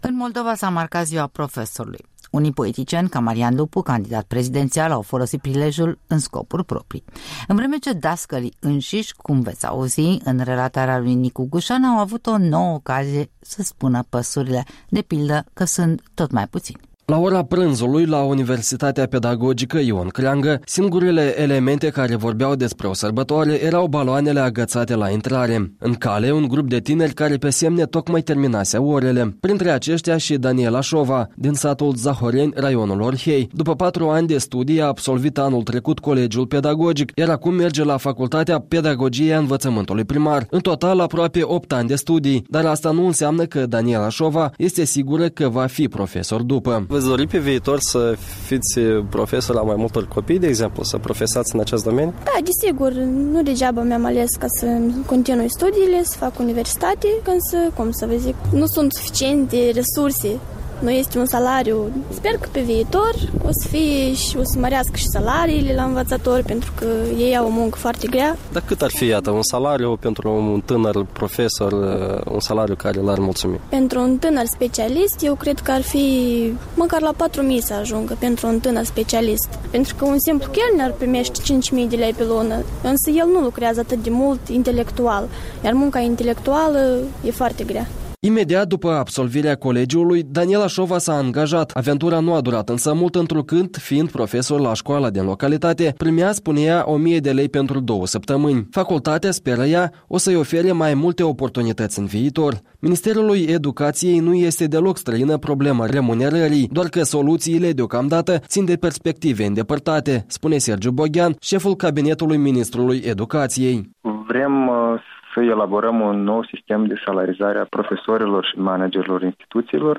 Opinii și date culese la Universitatea Pedagogică „Ion Creangă”, la Chișinău.
Un reportaj de Ziua Profesorului